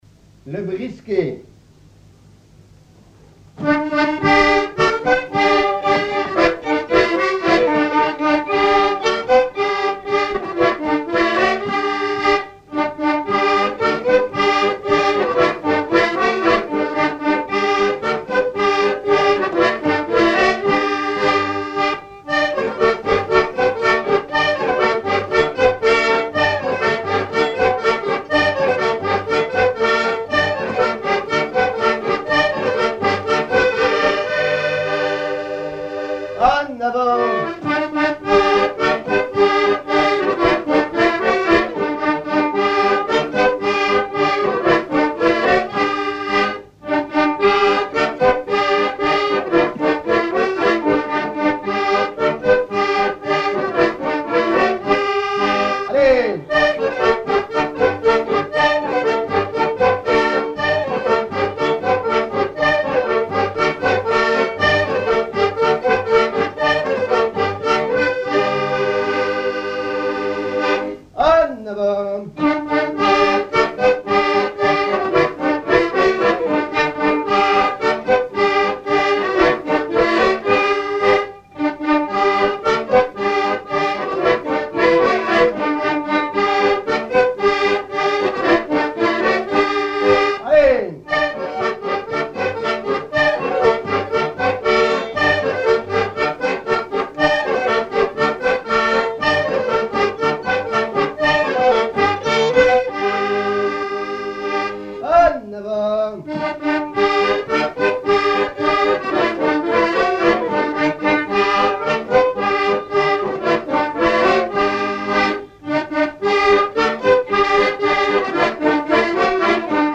danse : brisquet
Pièce musicale inédite